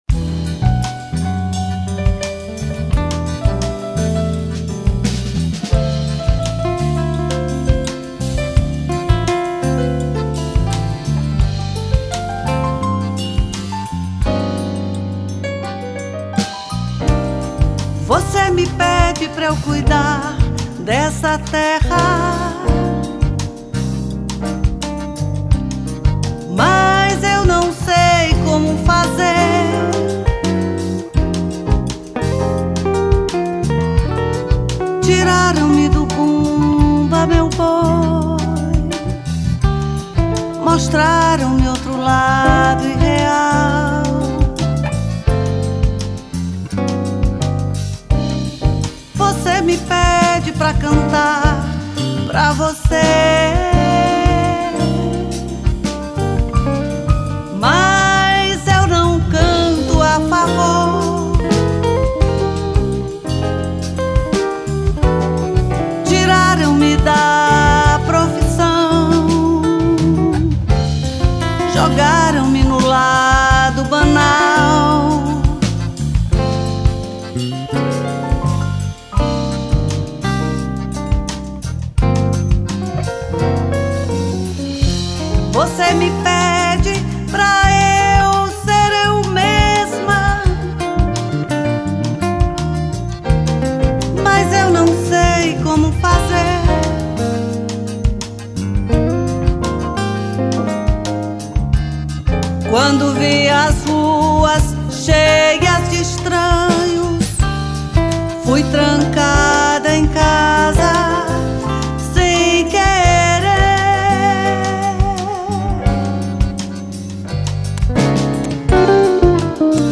1360   04:47:00   Faixa:     Jazz
Guitarra, Violao Acústico 6
Acoordeon, Teclados
Bateria
Baixo Elétrico 6